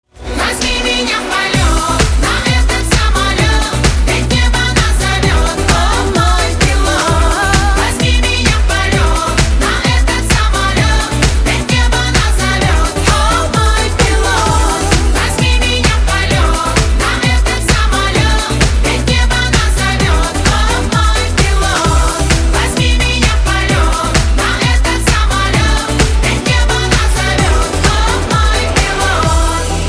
• Качество: 192, Stereo
громкие
зажигательные
заводные